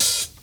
pcp_openhihat04.wav